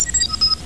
Door Chime - 15k
chime.wav